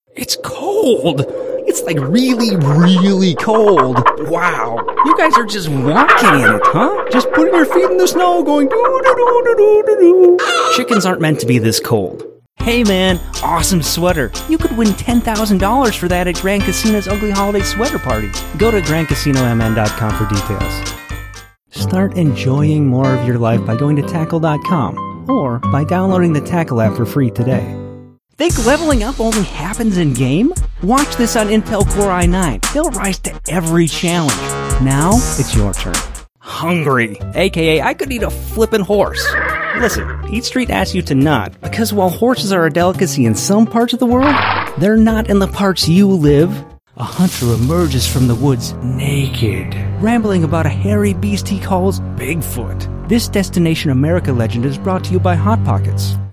Male
Yng Adult (18-29), Adult (30-50)
Radio Commercials
Commercial Demo